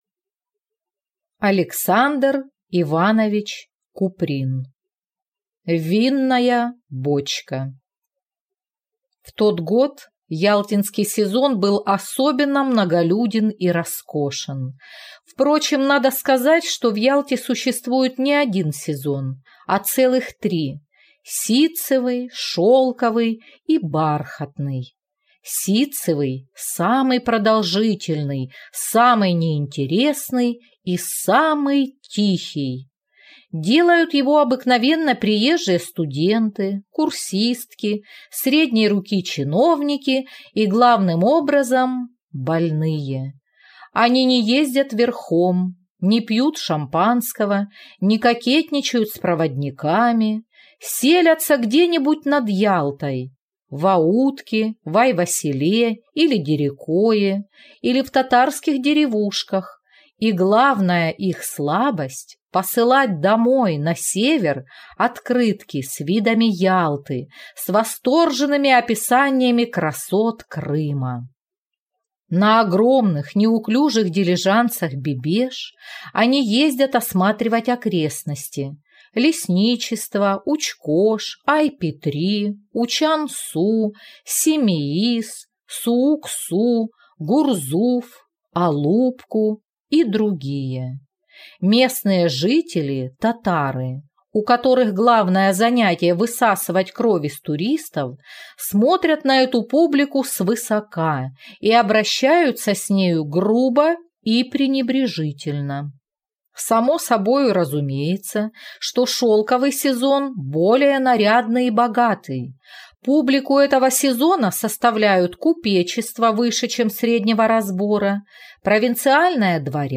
Аудиокнига Винная бочка | Библиотека аудиокниг